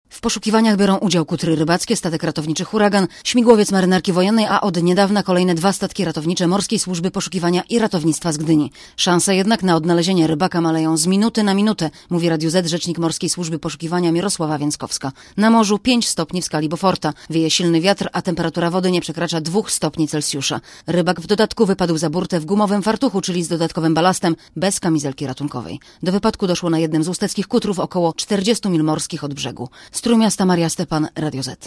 relacji